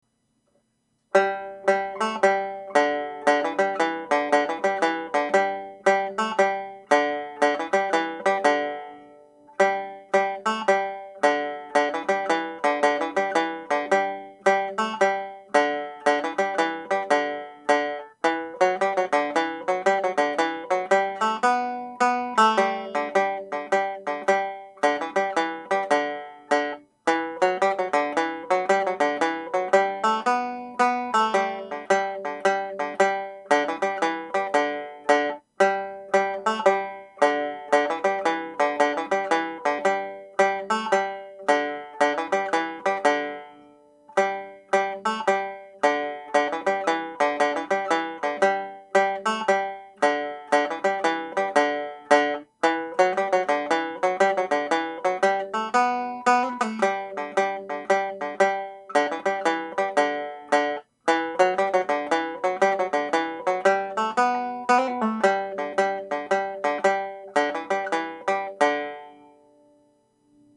(harmony)
Harmony part played at normal speed